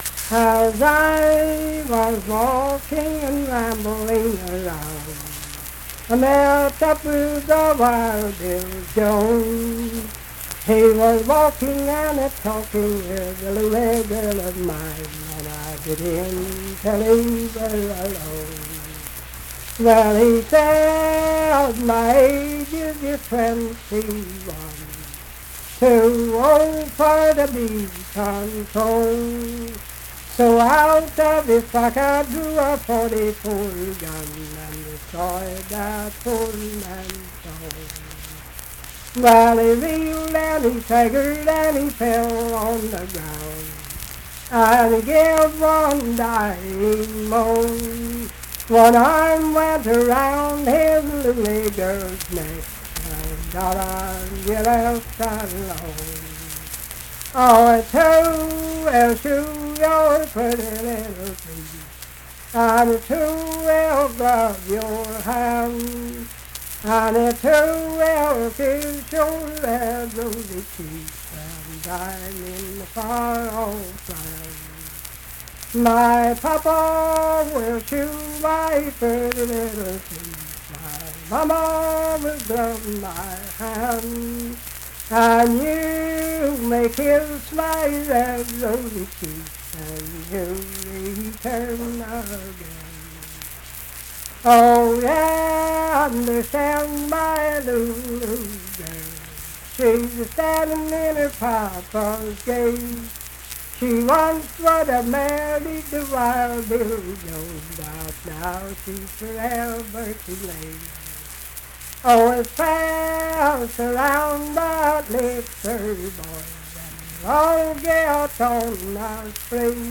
Unaccompanied vocal music
Verse-refrain 7(4).
Performed in Ivydale, Clay County, WV.
Voice (sung)